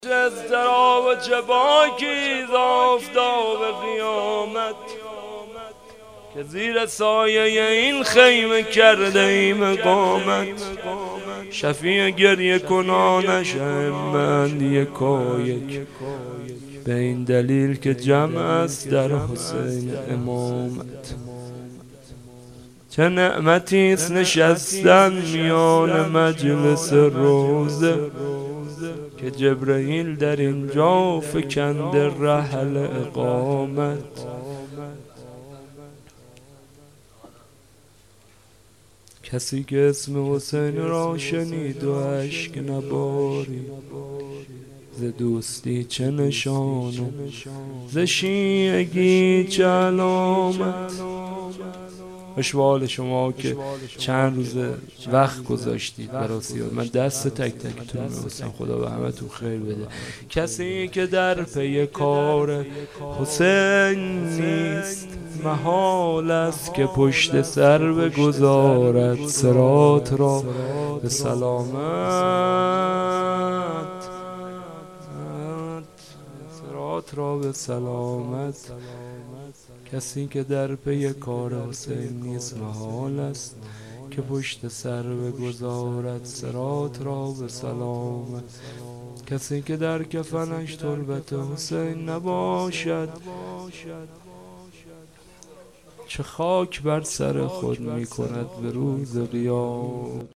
شب اول - غزل - چه اضطراب و چه باکی